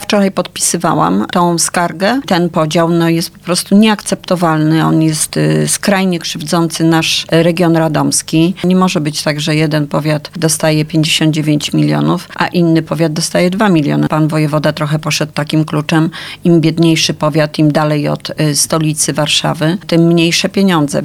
Parlamentarzyści PISu oczekują zmiany podejścia wojewody, nazywając podział środków skandalicznym.  Sytuację komentuje poseł Anna Kwiecień w Mocnej Rozmowie: